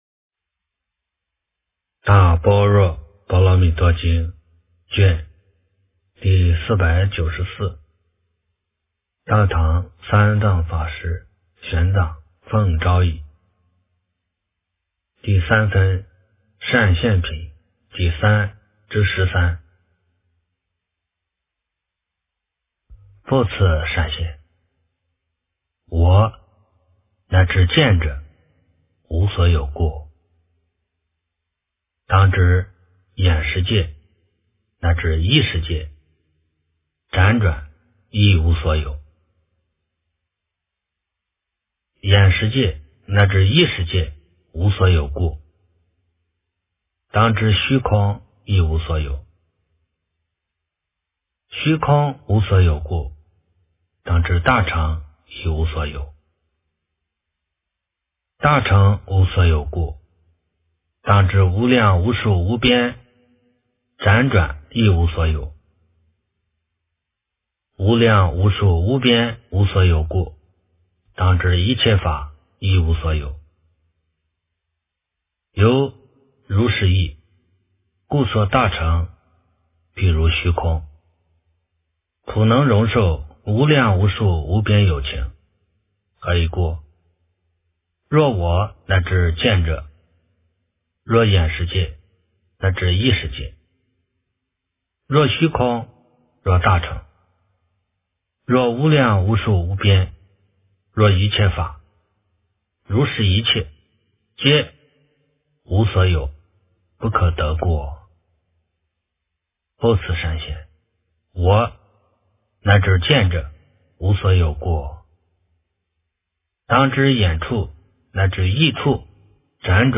大般若波罗蜜多经第494卷 - 诵经 - 云佛论坛